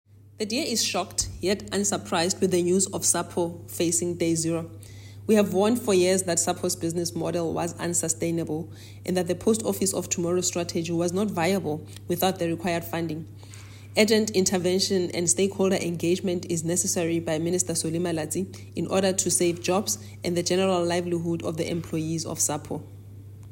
soundbite by Tsholofelo Bodlani MP.